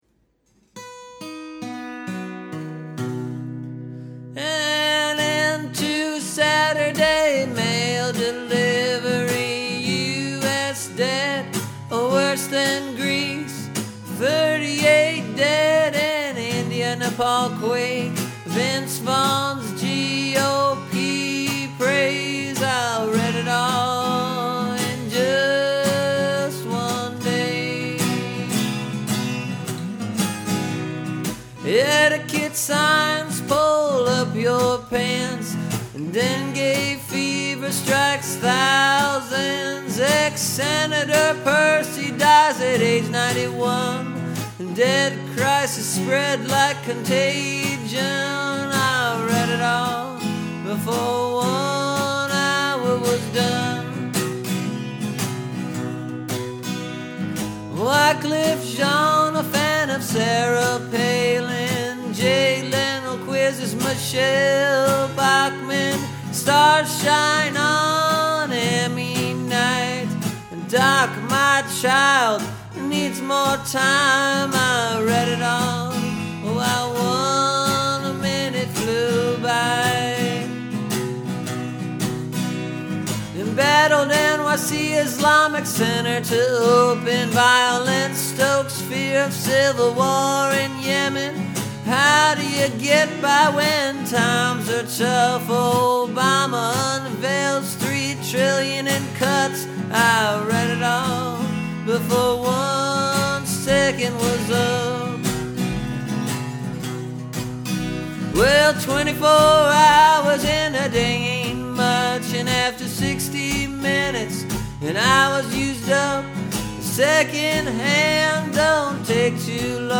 Here’s the new talkin’ blues for this week. All the highly relevant headlines that you just need to know.
You can tell that my voice is still recovering, right?